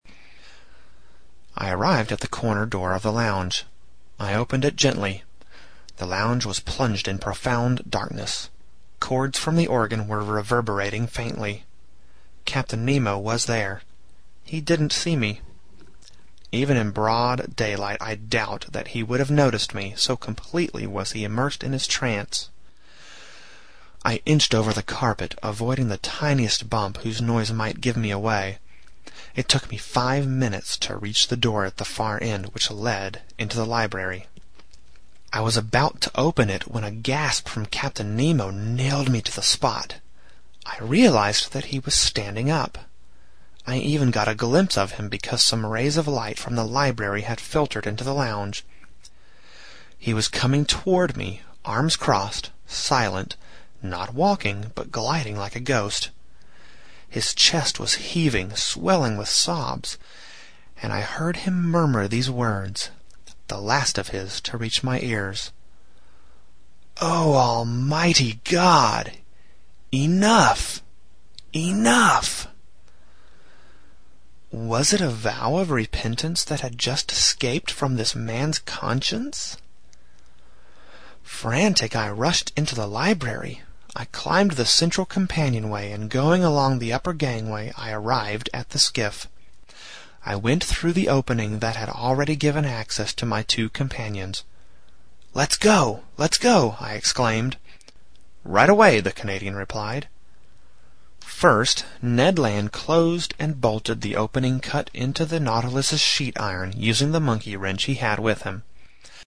在线英语听力室英语听书《海底两万里》第562期 第35章 尼摩船长的最后几句话(9)的听力文件下载,《海底两万里》中英双语有声读物附MP3下载